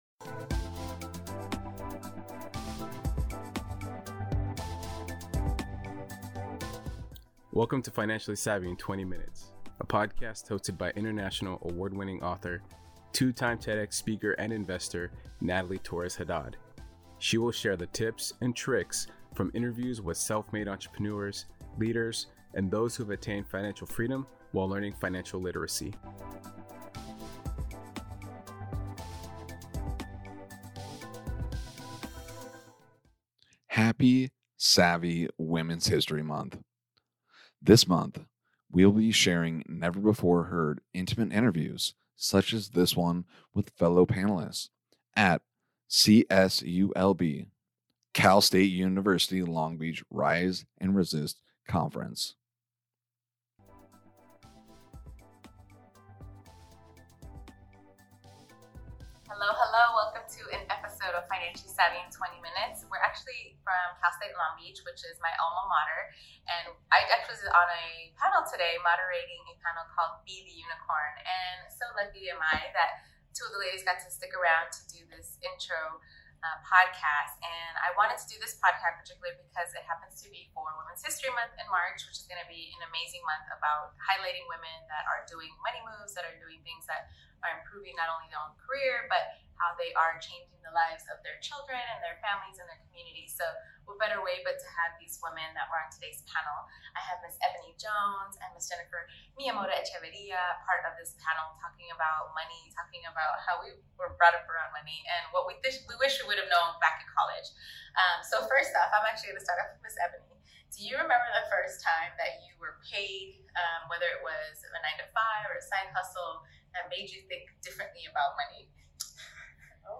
Breaking taboos around money with kids panel